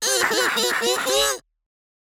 Orbulon's voice from the official Japanese site for WarioWare: Move It!
WWMI_JP_Site_Orbulon_Voice.wav